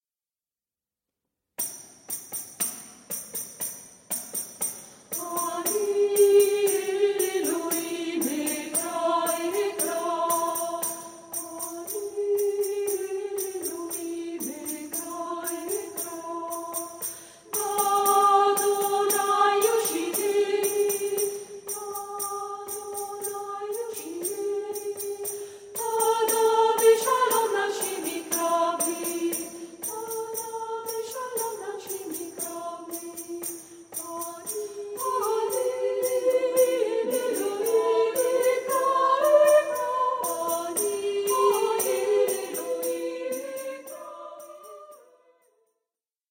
gruppo vocale